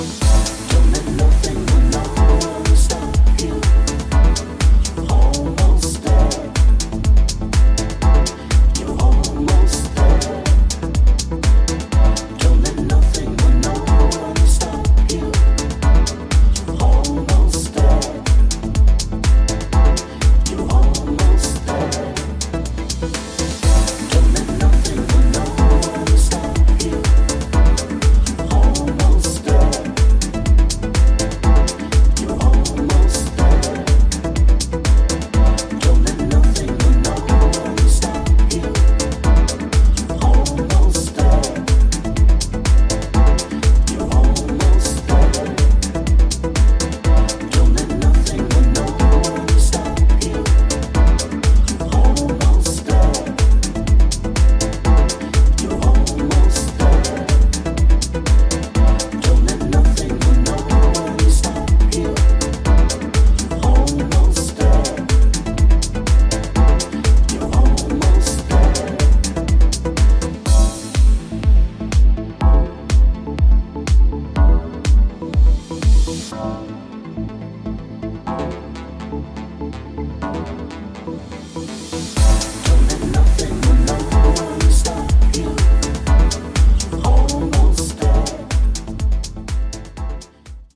ソリッドなトラックにウォームな奥行きを与えています！